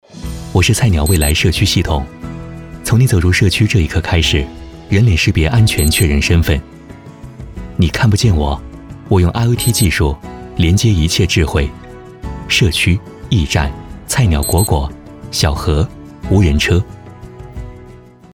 男声配音
稳重 科技 大气 抒情